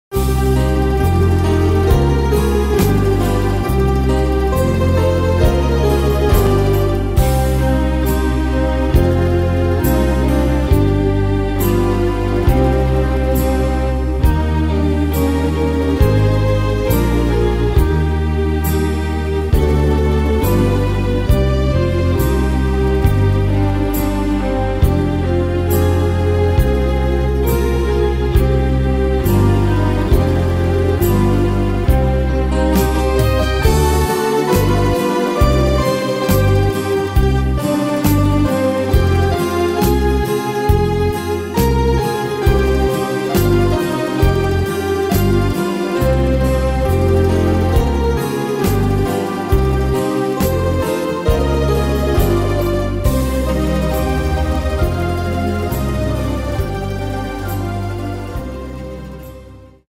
Tempo: 136 / Tonart: C-;Moll